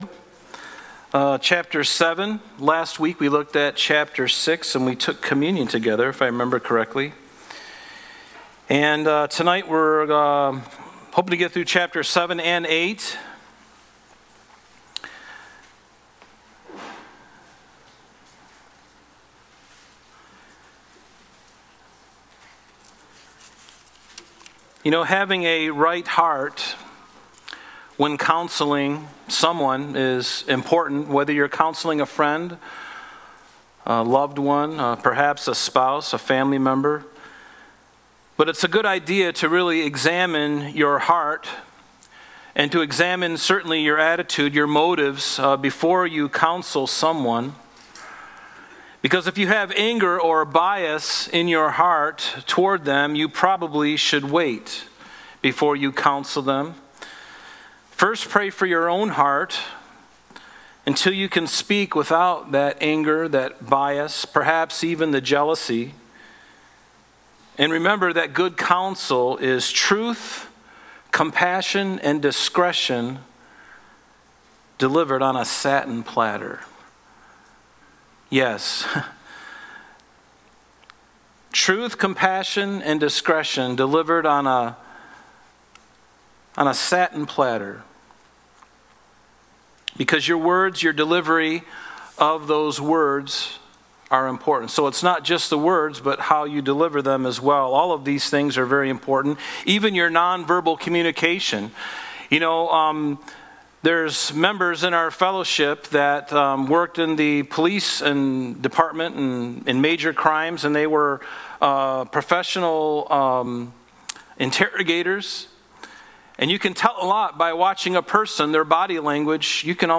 Thursday Night Bible Study